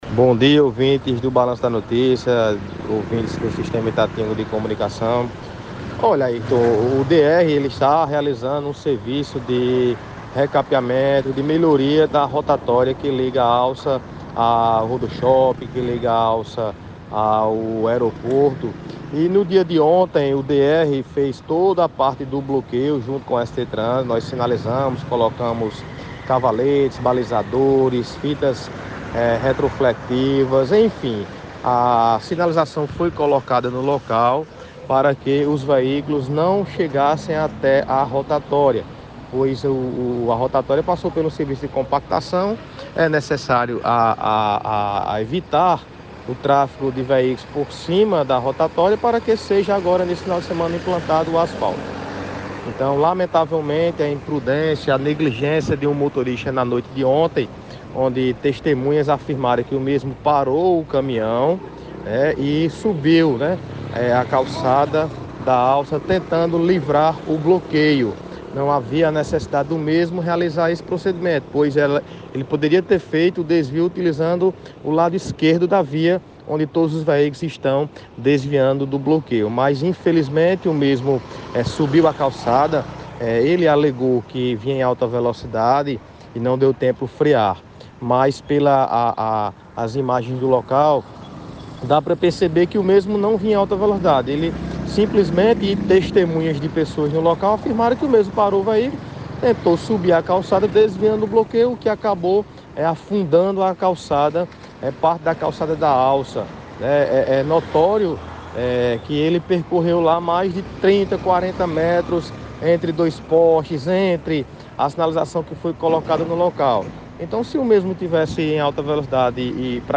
O superintendente da Superintendência de Trânsito e Transporte de Patos PB – STTrans, Elucinaldo Laurindo, disse nesta quinta-feira (30), ao Balanço da Notícia, da Itatiunga FM, que houve imprudência do motorista do Caminhão Bitrem que subiu a calçada da Alça Sudeste, na noite dessa quarta-feira (29).